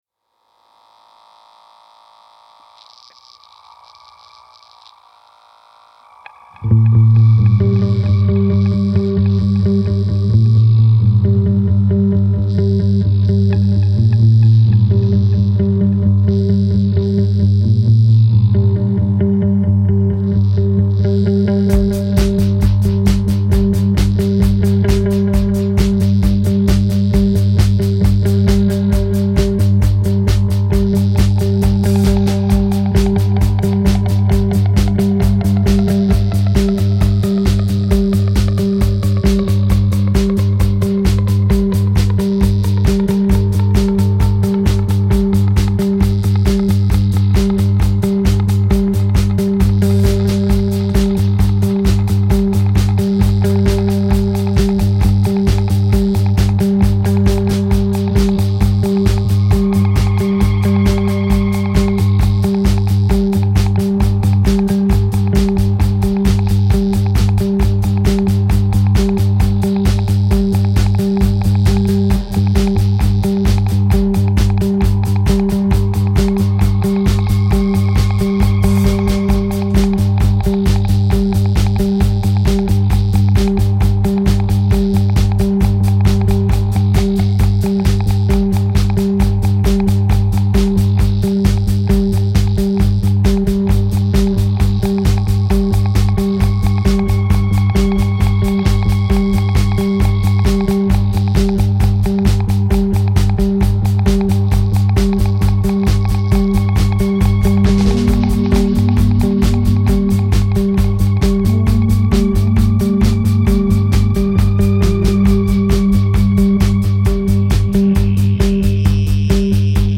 Comment on dit Krautrock en portugais ?